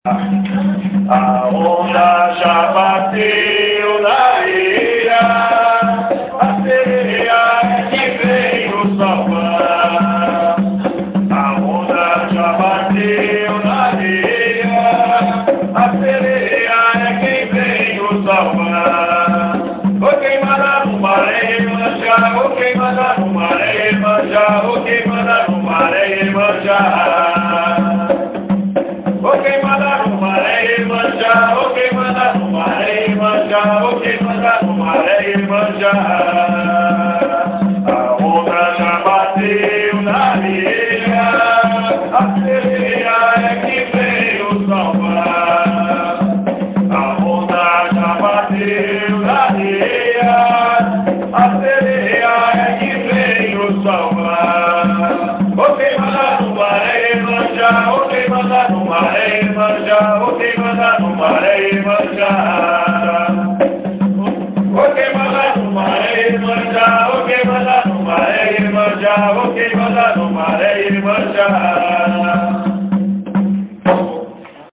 Ensaio Gira – Casa Vó Maria